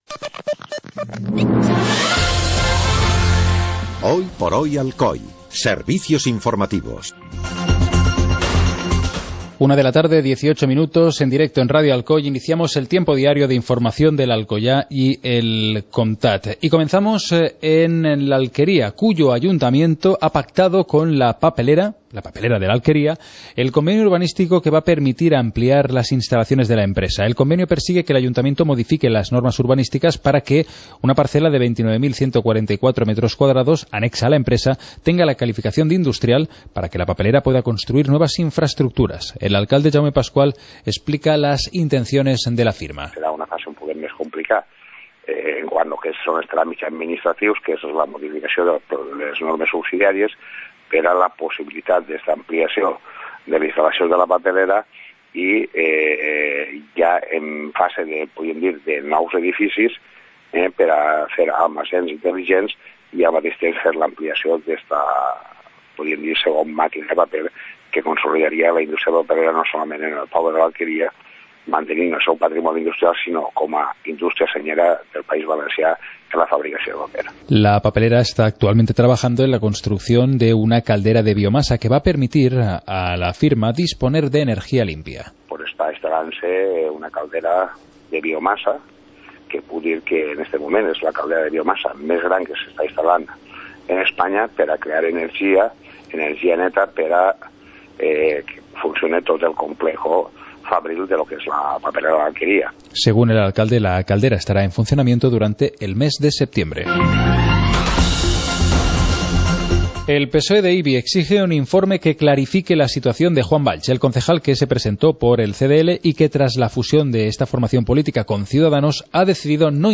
Informativo comarcal - miércoles, 09 de julio de 2014